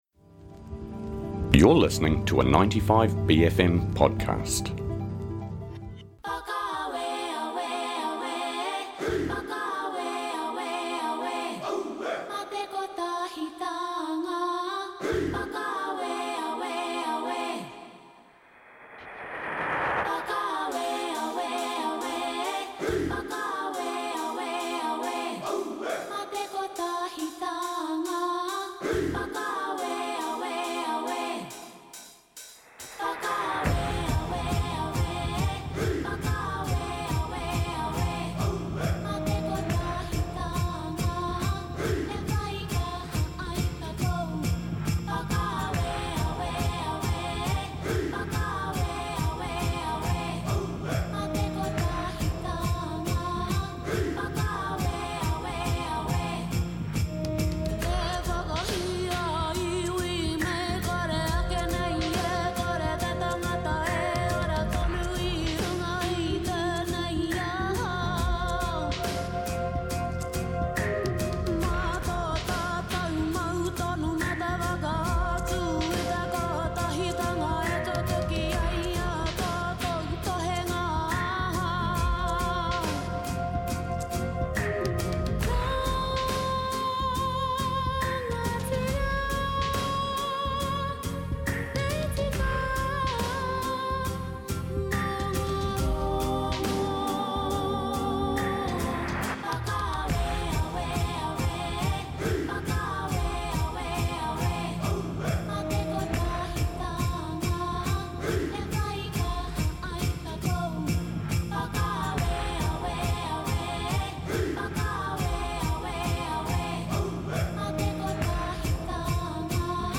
I/V/ w Dame Hinewehi Mohi: 20 May, 2024